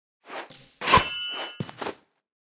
Media:Sfx_Anim_Classic_Barbarian King.wavMedia:Sfx_Anim_Super_Barbarian King.wavMedia:Sfx_Anim_Ultra_Barbarian King.wavMedia:Sfx_Anim_Ultimate_Barbarian King.wav 动作音效 anim 在广场点击初级、经典、高手、顶尖和终极形态或者查看其技能时触发动作的音效
Sfx_Anim_Classic_Barbarian_King.wav